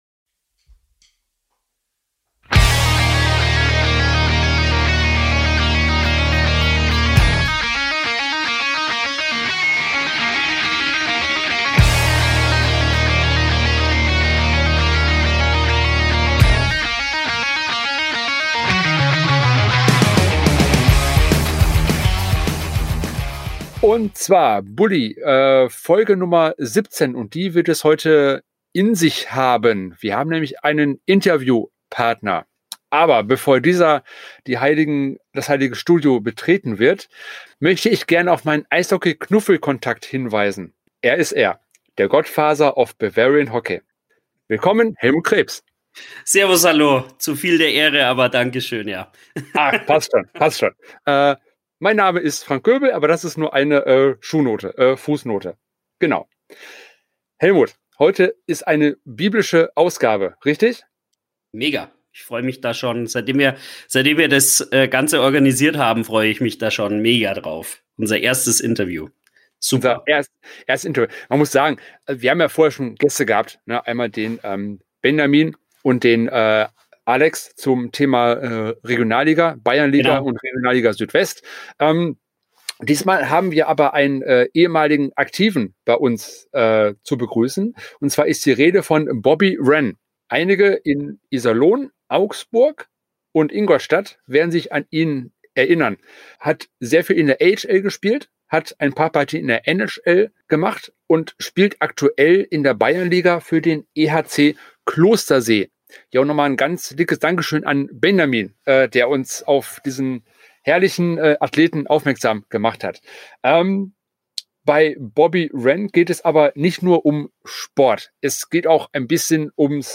Bully #017 Interview